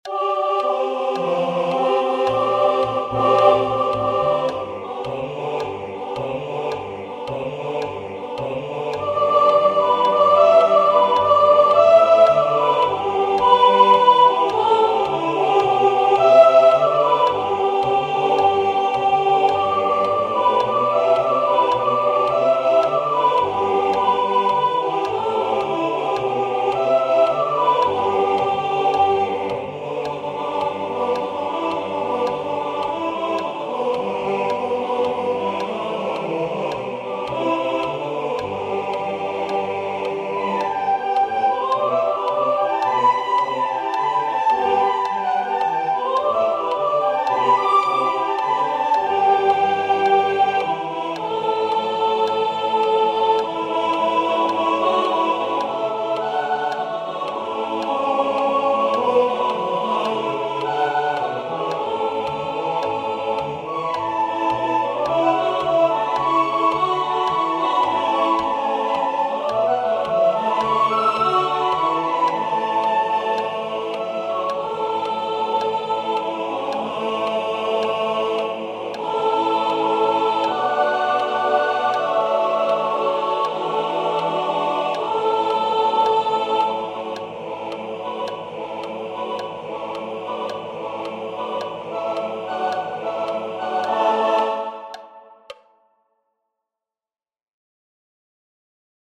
This page contains rehearsal files for choir members.